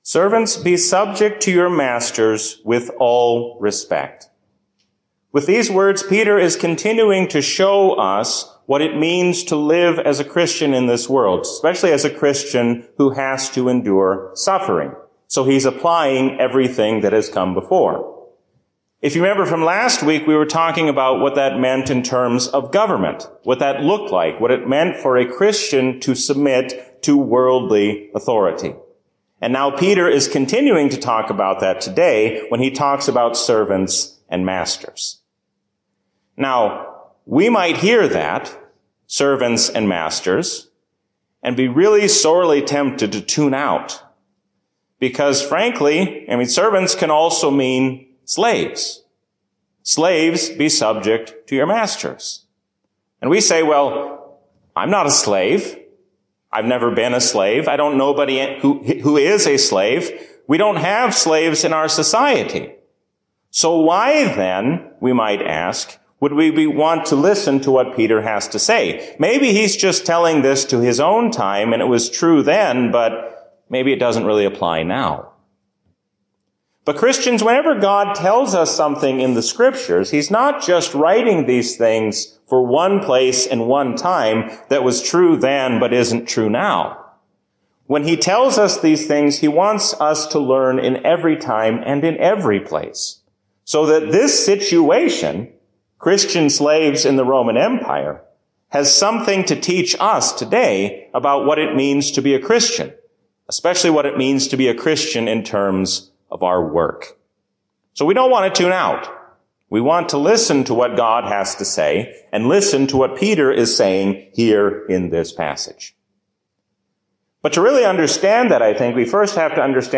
A sermon from the season "Pentecost 2023." If we are forced to choose between our job and Jesus, we must hold fast to Him.